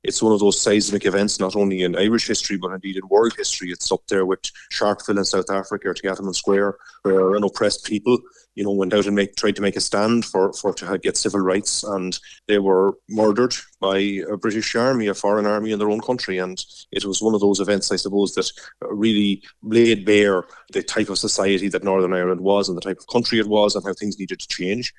Sinn Féin’s justice spokesman and South Donegal TD, Martin Kenny, says Bloody Sunday laid bare the type of society that existed in Northern Ireland at the time: